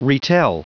Prononciation du mot retell en anglais (fichier audio)
Prononciation du mot : retell